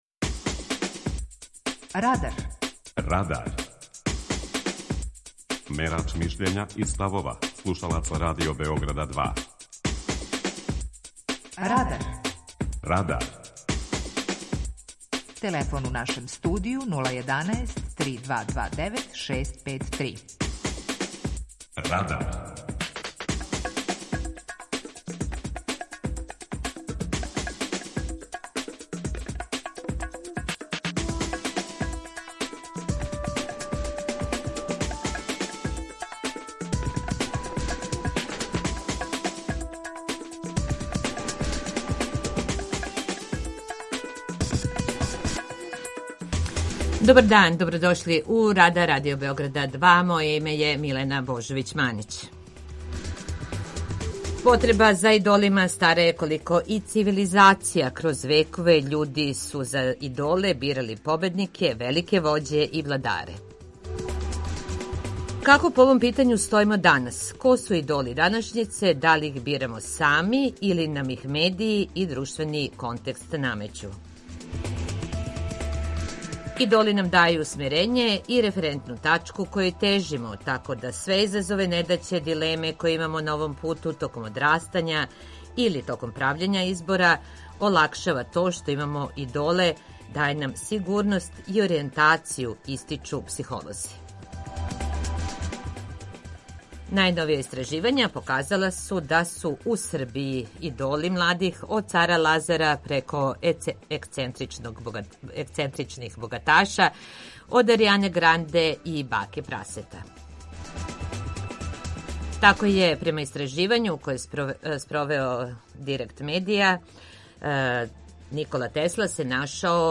Питање Радара је „Ко су идоли младих?" преузми : 19.13 MB Радар Autor: Група аутора У емисији „Радар", гости и слушаоци разговарају о актуелним темама из друштвеног и културног живота.